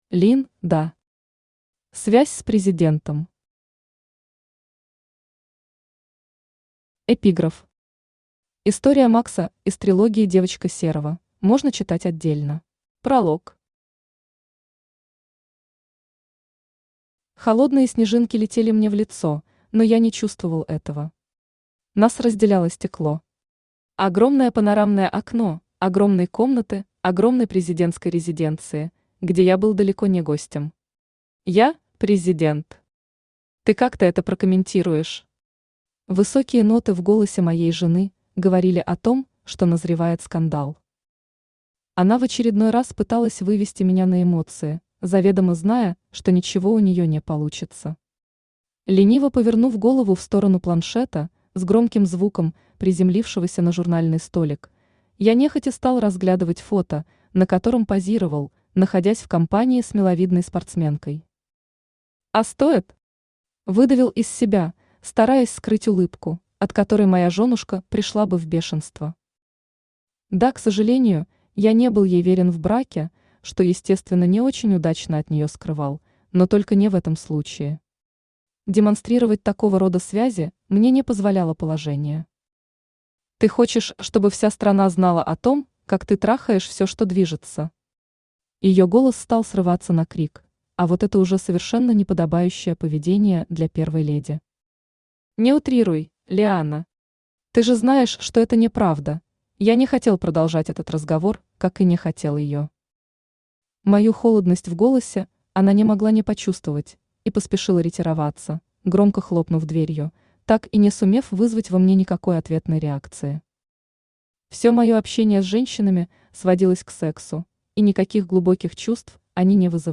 Аудиокнига Связь с президентом | Библиотека аудиокниг
Aудиокнига Связь с президентом Автор Лин Да Читает аудиокнигу Авточтец ЛитРес.